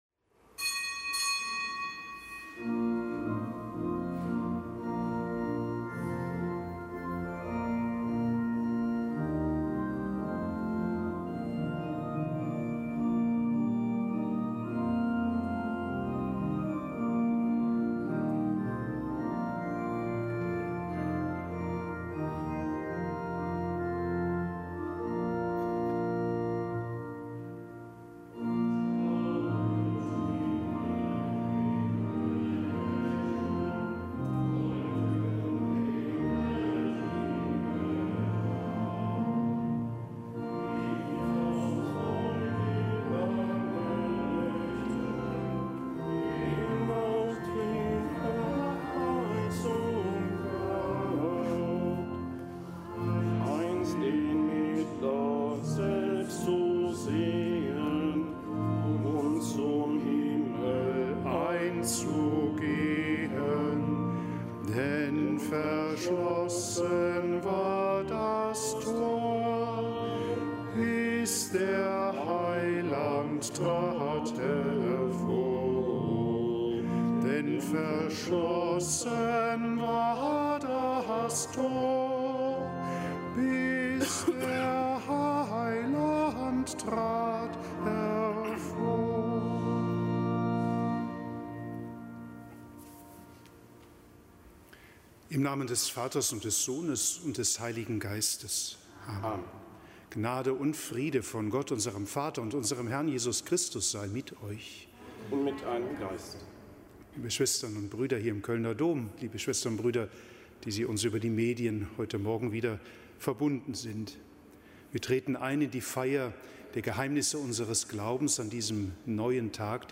Kapitelsmesse aus dem Kölner Dom am Donnerstag der zweiten Adventswoche. Nichtgebotener Gedenktag des Heiligen Damasus I., einem Papst.